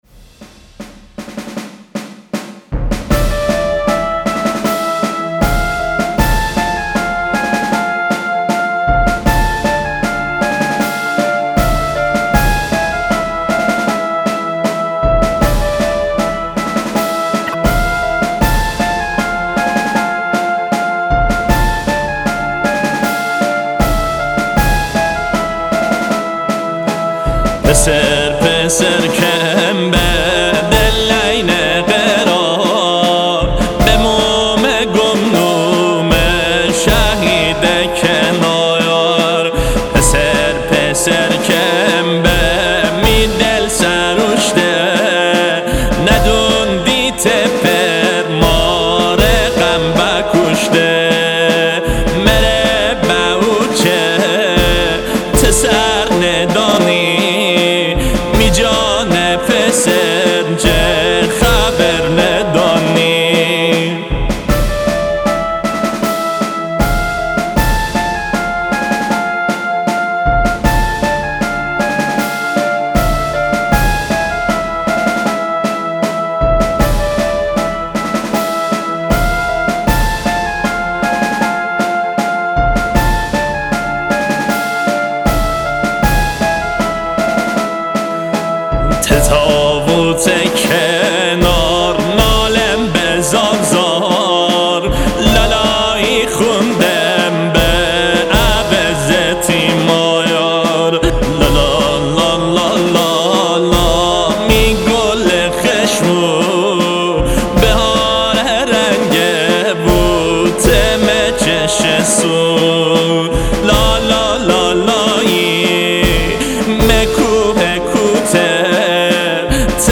لهجه مازندرانی